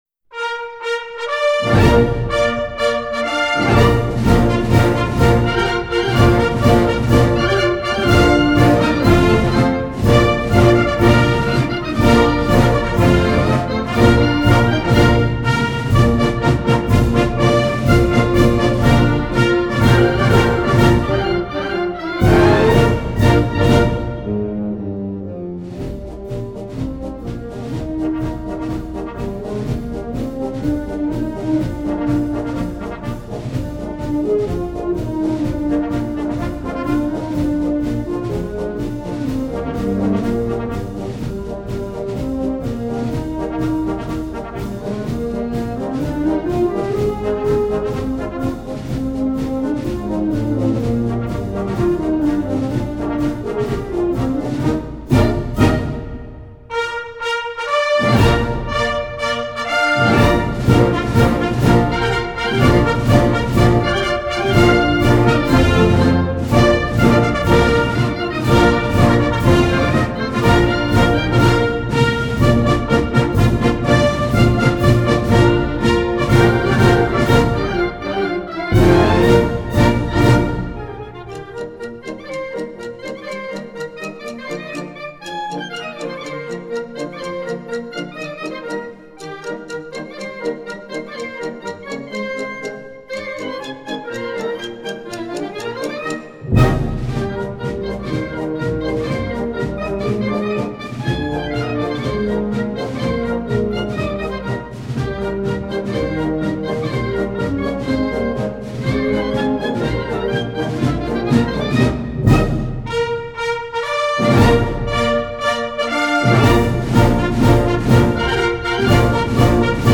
Toques de ordenanza